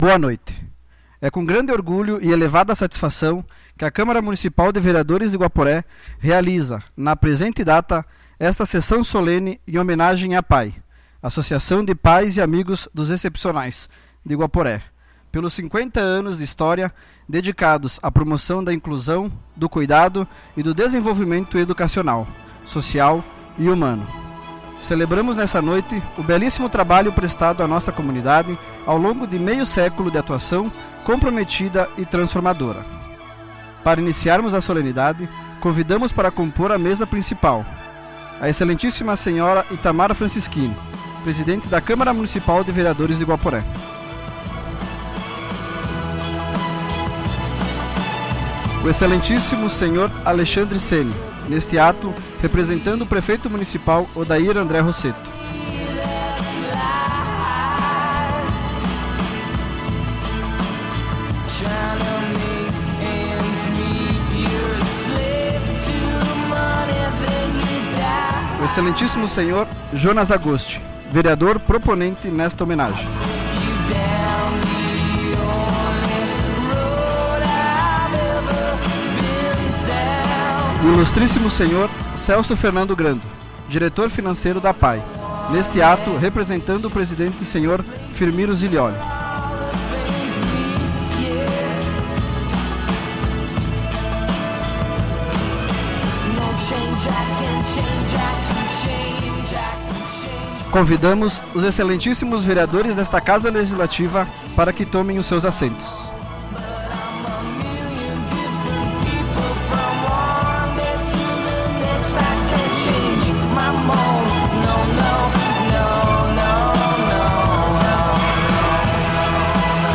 Sessão Solene em homenagem a APAE